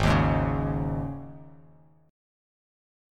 Absus4 chord